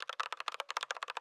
SFX_Typing_02.wav